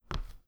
hard-footstep2.wav